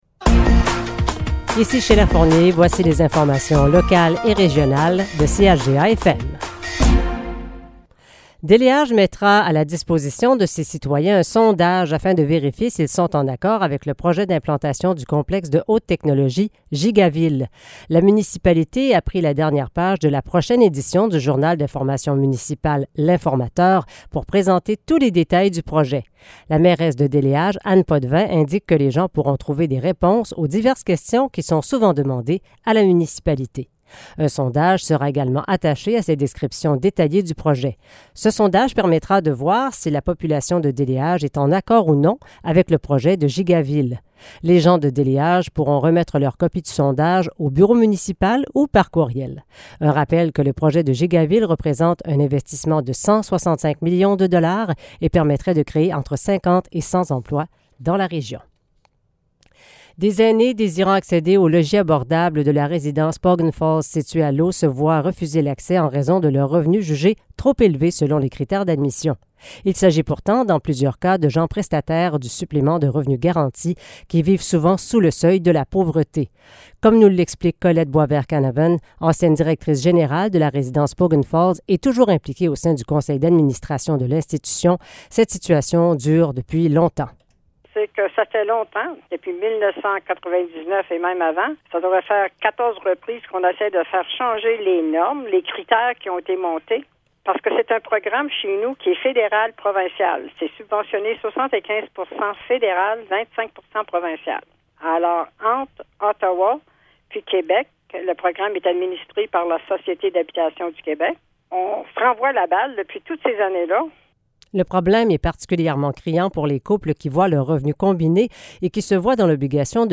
Nouvelles locales - 13 septembre 2022 - 12 h